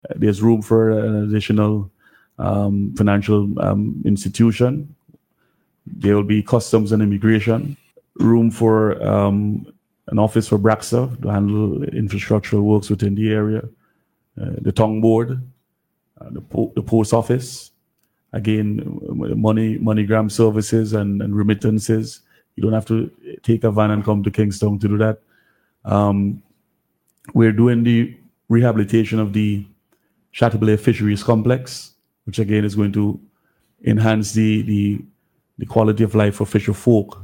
Minister James made the announcement while speaking on radio recently.